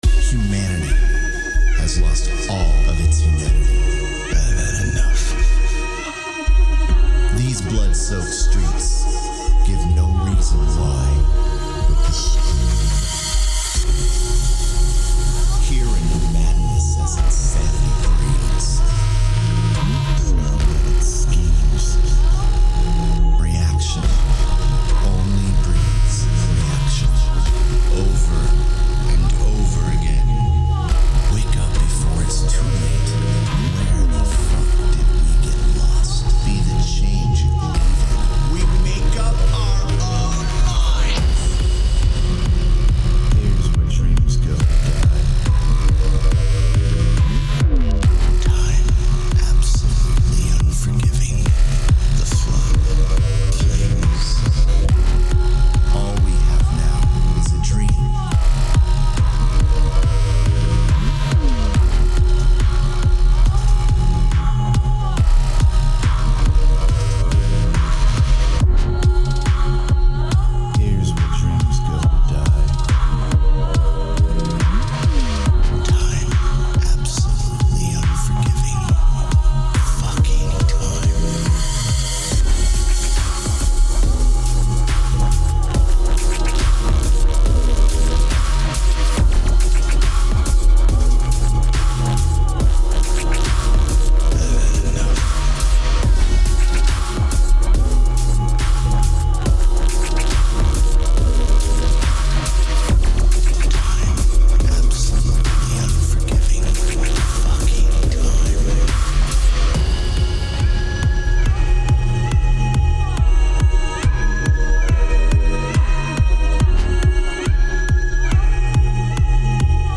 Tempo 140BPM (Allegro)
Genre Deep Dark Dubstep
Type Vocal Music
Mood Conflicting [Aggressive/energetic/sorrow]